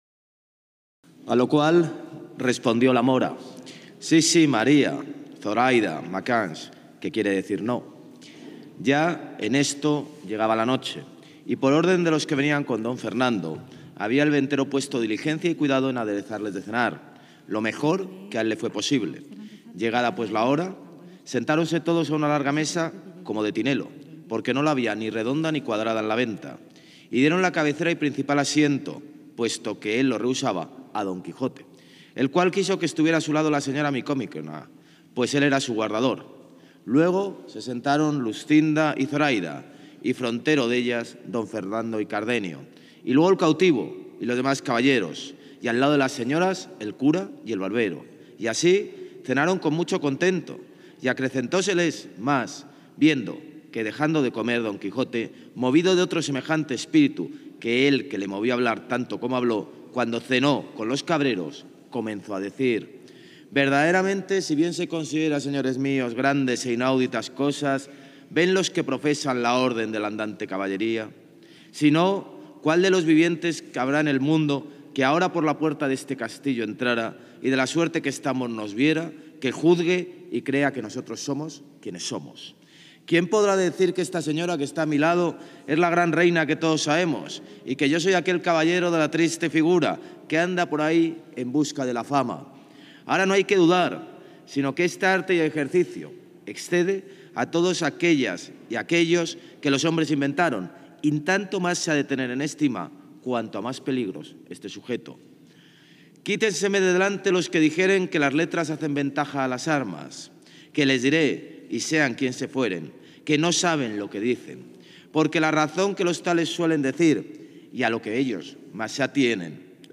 Almeida participa en la XXVI Lectura Continuada del Quijote en el Círculo de Bellas Artes - Ayuntamiento de Madrid
En la celebración del Día del Libro
Nueva ventana:José Luis Martínez-Almeida, alcalde de Madrid
JLMartinezAlmeida-LecturaQuijote-22-04.mp3